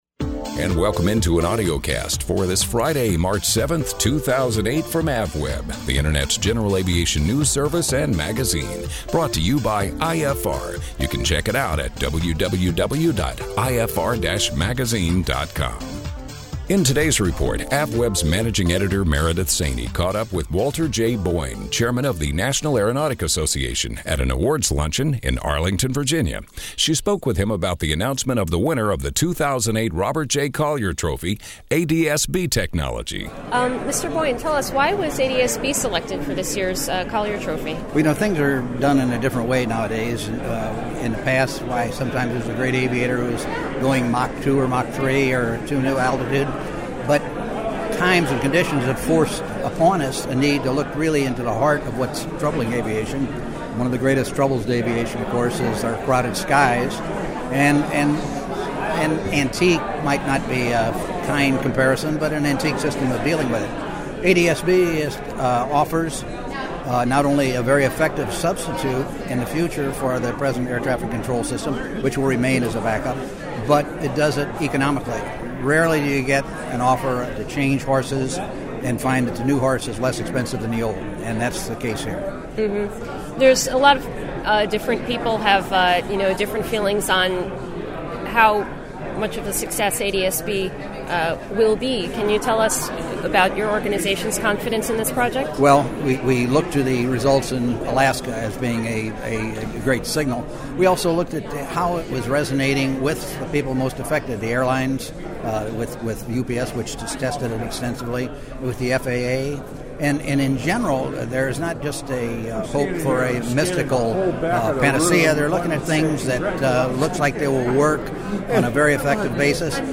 On Site at NAA’s 2008 Awards Luncheon